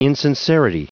Prononciation du mot insincerity en anglais (fichier audio)
Prononciation du mot : insincerity
insincerity.wav